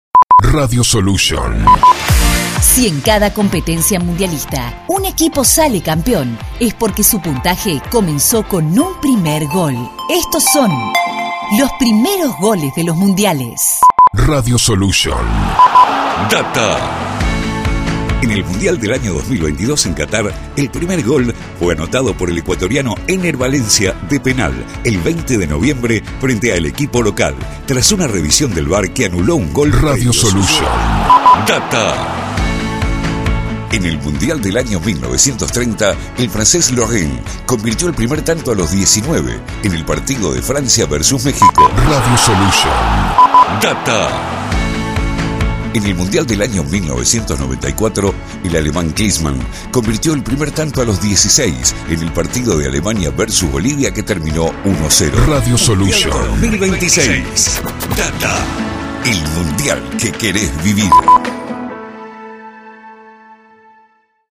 Editados con Música y Efectos
Producción confeccionada a 1 voz